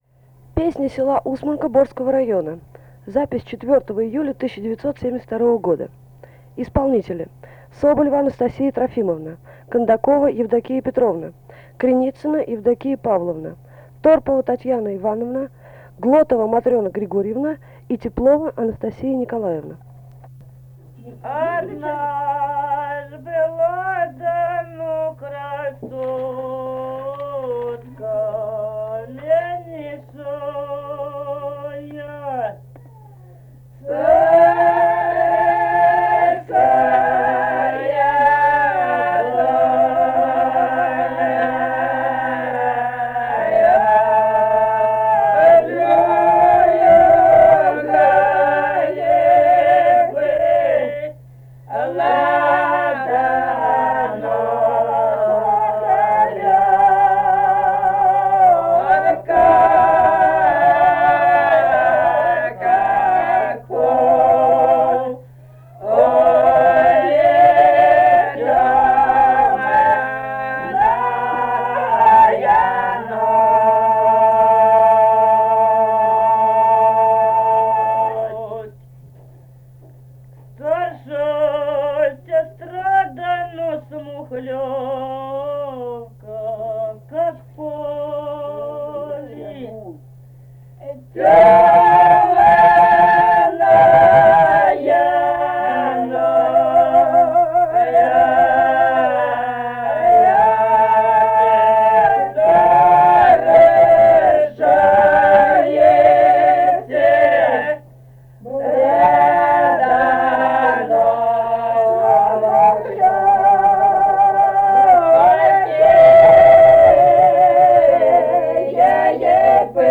полевые материалы
Самарская область, с. Усманка Борского района, 1972 г. И1317-01